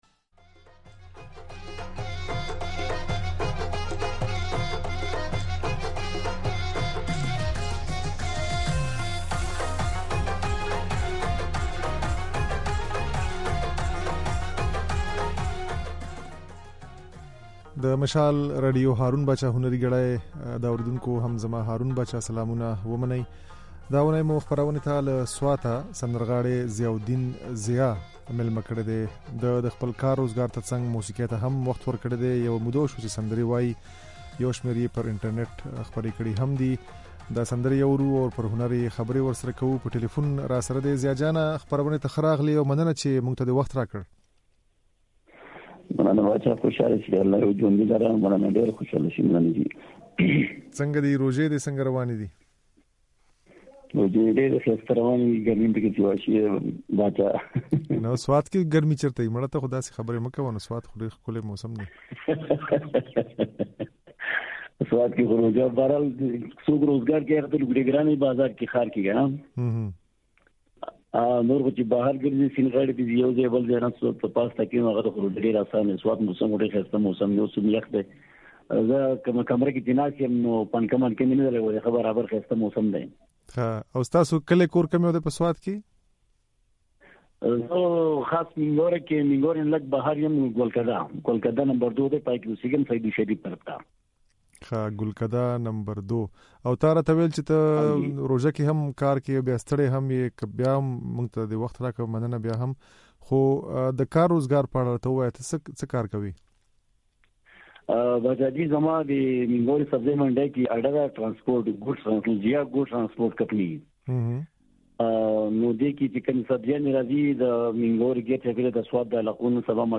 ځينې سندرې يې د غږ په ځای کې اورېدای شئ.